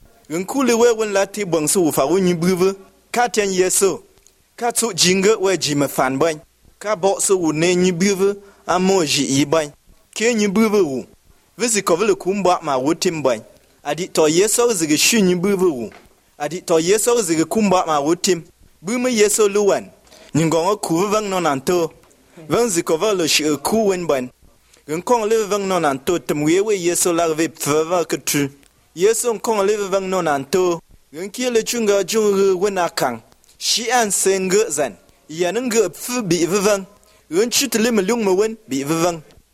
It has all the syllable structure, tones, central vowels and implosive voiced bilabial stops as well as voiced bilabial fricatives that I seem to remember from papers on Tiv phonology.